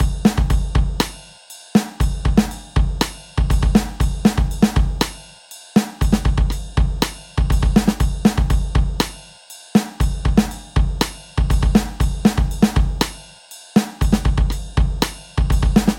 Aint No Stopping Drums 120bpm
在弗吉尼亚州的工作室制作
标签： 120 bpm Rock Loops Drum Loops 2.69 MB wav Key : Unknown
声道立体声